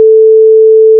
**🔊 SFX PLACEHOLDERS (23 WAV - 1.5MB):**
Farming (8): plant_seed, water_crop, harvest, dig, scythe, mine, chop, cow
**⚠  NOTE:** Music/SFX are PLACEHOLDERS (simple tones)
water_crop.wav